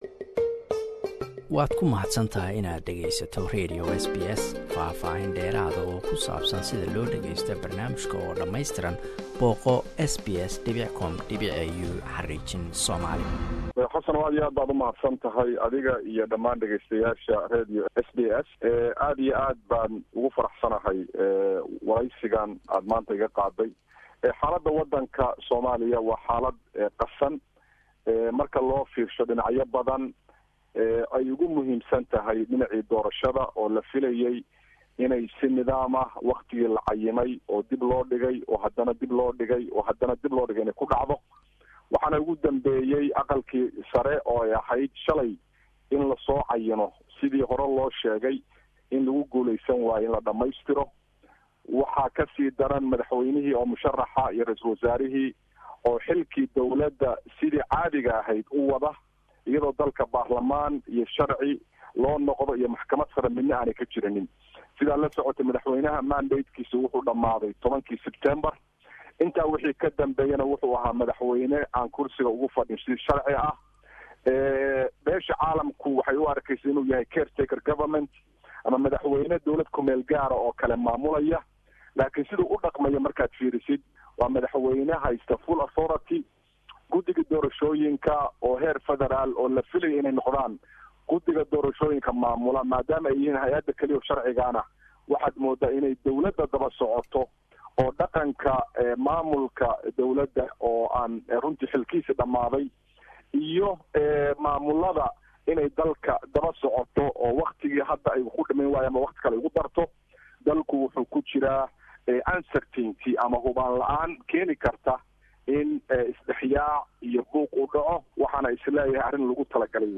Cali Xaaji waa wasiirkii hore ee wasaarada waxbarashada Puntland, hadana u sharaxan jagada madaxweyne ee federalka. Wuxuu aad ugu dhaliilay qaabka madaxweyneha hada jira uu u wado nidaamka doorashada.